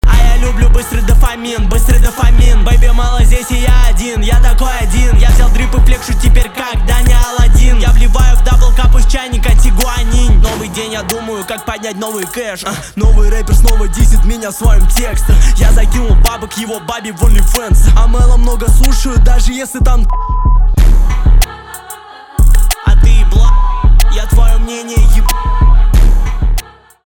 русский рэп , битовые , басы , крутые , качающие
жесткие